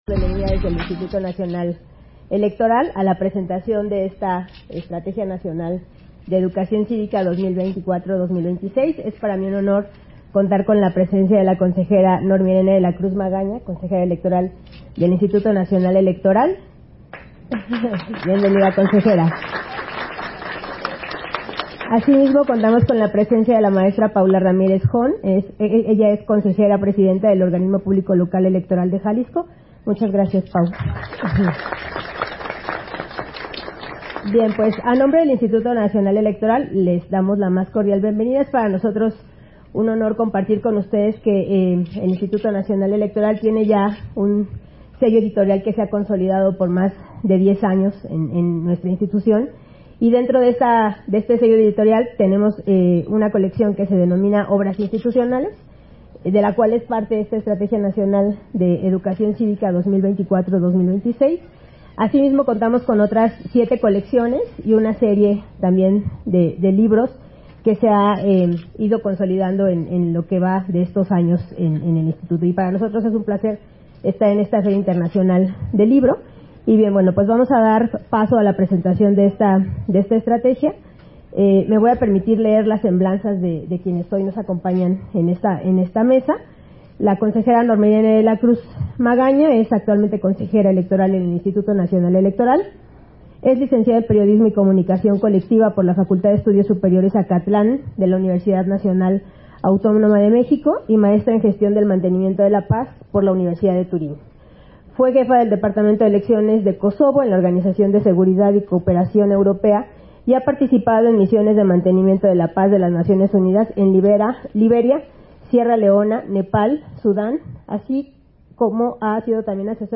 Versión estenográfica de la presentación de la Estrategia Nacional de Educación Cívica (ENCÍViCA) 2024-2026, FIL de Guadalajara 2024